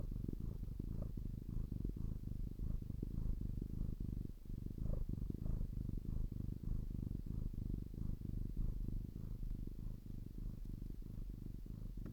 Koci ASMR:
mruczenie.mp3